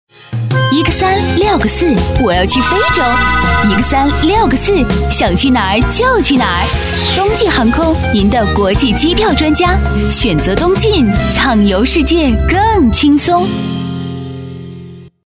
广告配音